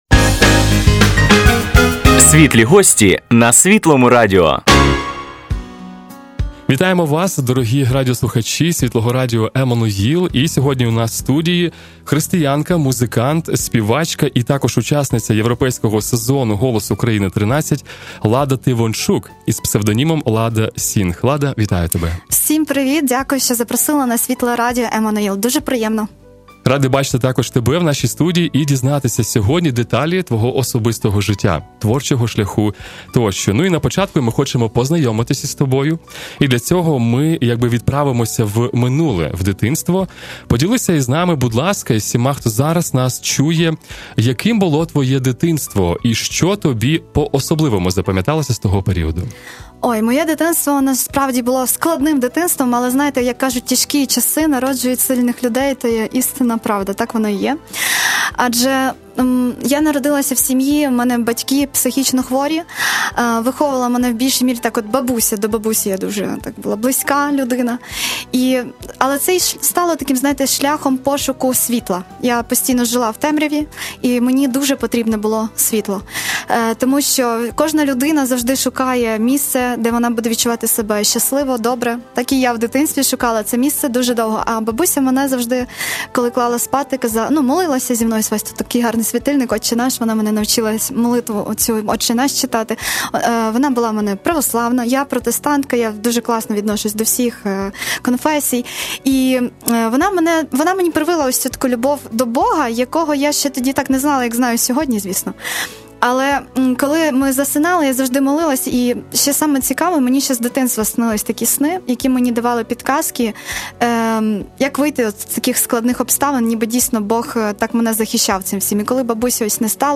Під час інтерв'ю порушуються питання самоідентичності, небезпек і благословінь слави, важливість відновлення особистого ресурсу...